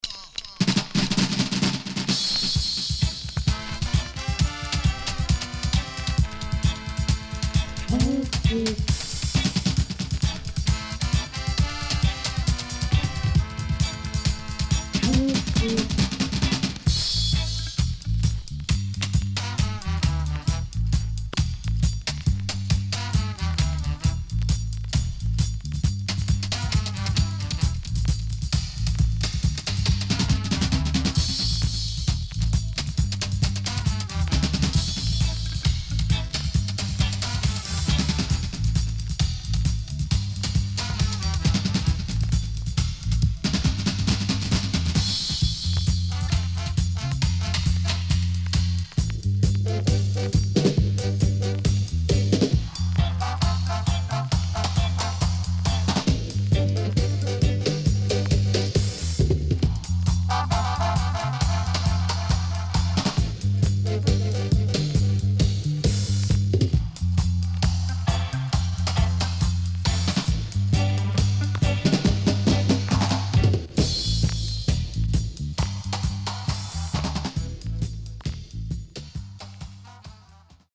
SIDE A:所々チリノイズがあり、少しプチノイズ入ります。